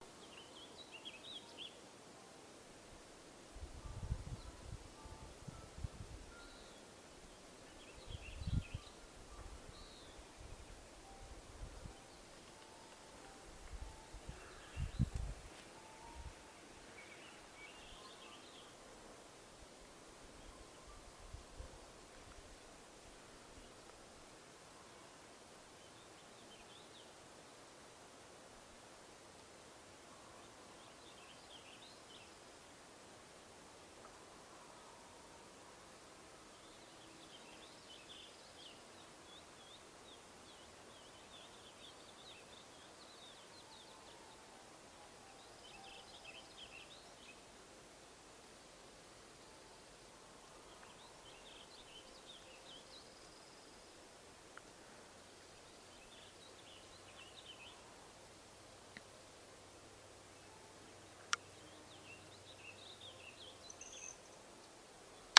森林氛围
描述：一分钟记录森林氛围。鸟类在后台发推文，它可以非常轻松。一些风也会出现。它还可以用于填充电影的音频。
Tag: 鸟类 户外 森林 室外 空气 气氛 大气